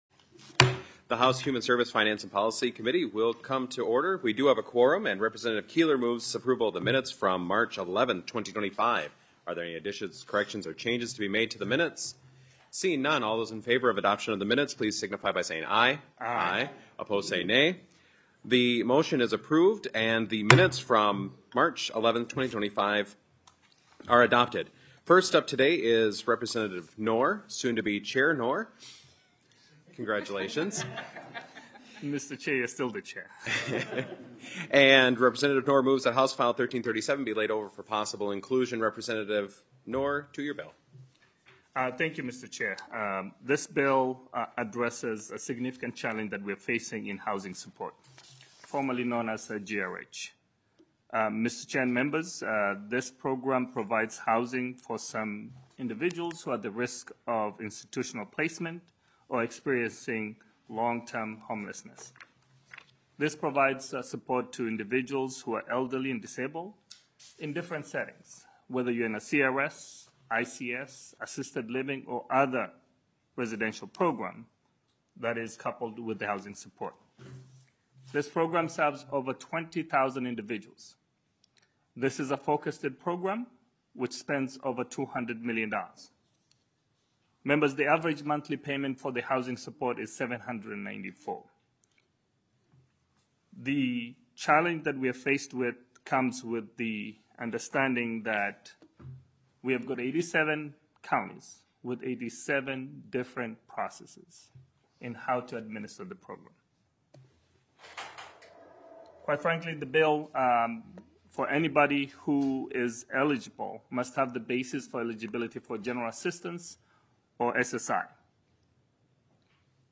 Human Services Finance and Policy FOURTEENTH MEETING - Minnesota House of Representatives